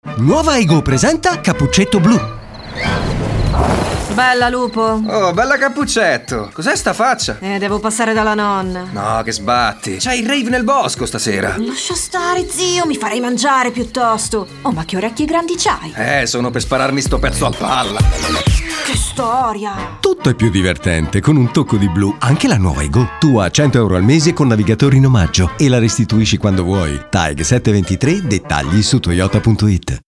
MAMMA Kinder Sorpresa TV
tv Kinder Sorpresa
-Locale chiuso fornito di pannelli fonoassorbenti